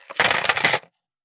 Gun Sold 003.wav